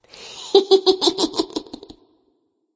Play Bee Cupid Laugh - SoundBoardGuy
Play, download and share Bee Cupid Laugh original sound button!!!!
beecupid_laugh_1.mp3